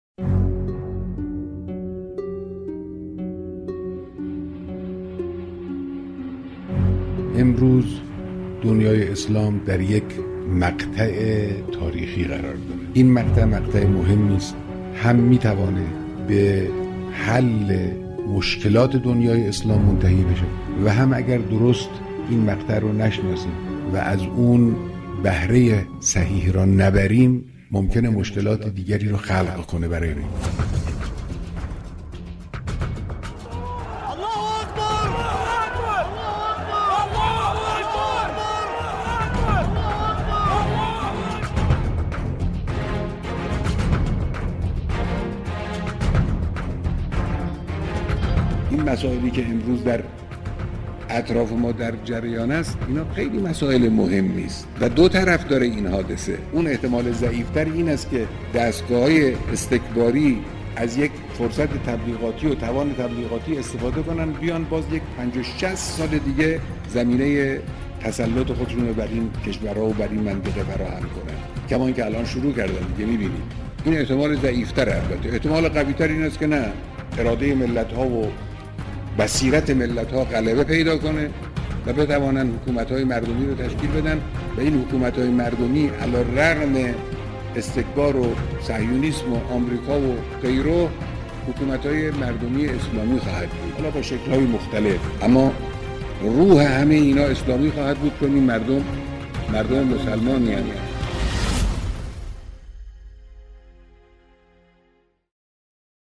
صوت سخنرانی